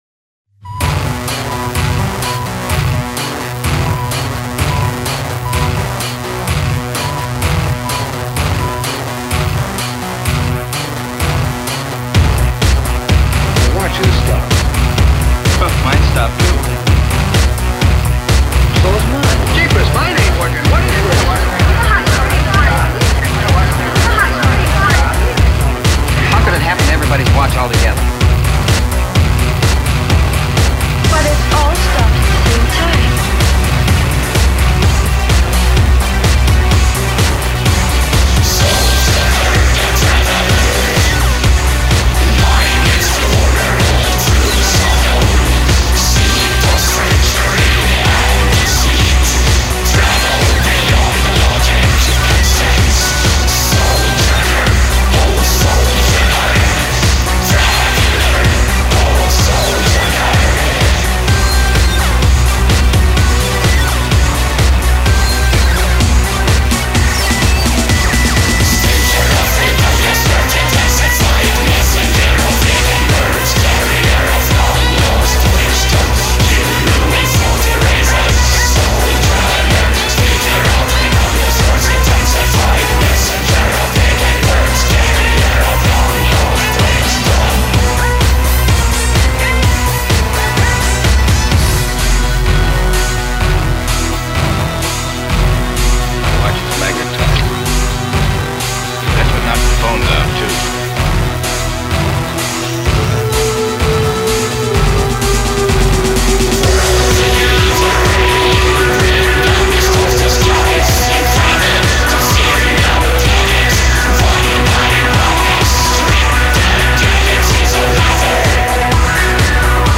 Dançante, inebriante e assustador.